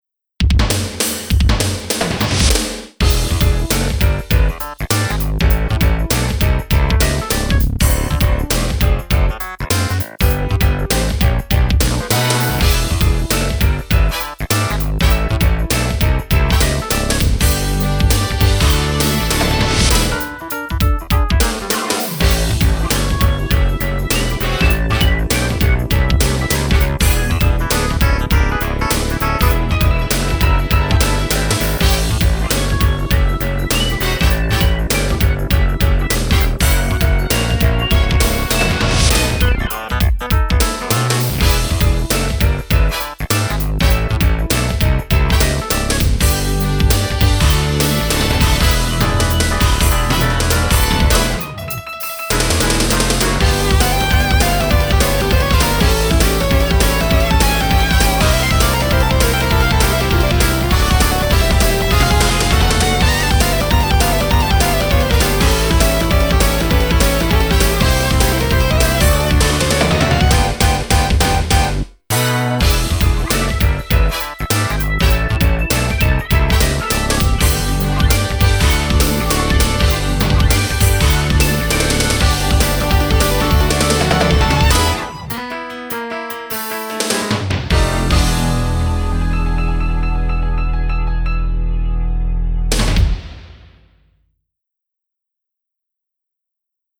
BPM200
Audio QualityMusic Cut
FUNK SHUFFLE